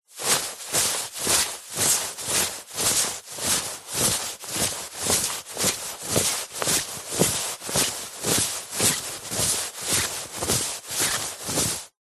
Звуки штанов
Шорох штанов при трении друг о друга